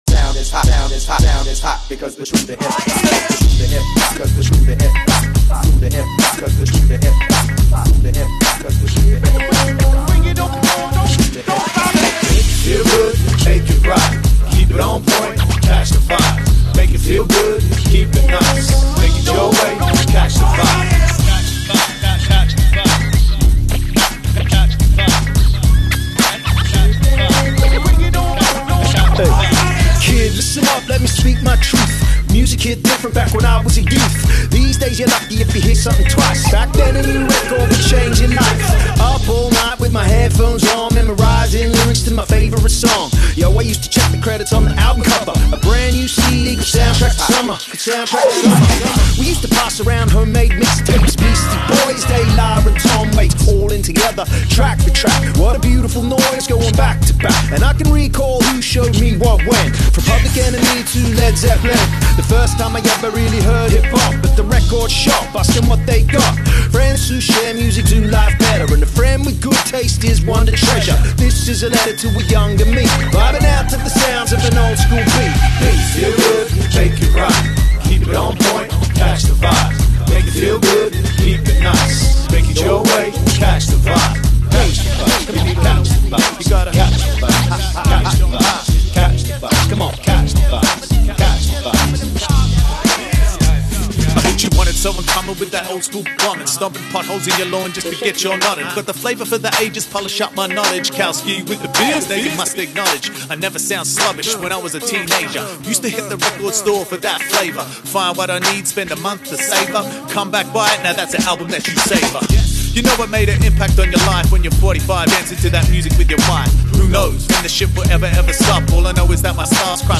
Aussie rap
Top-tier, straight-up Aussie rap bizness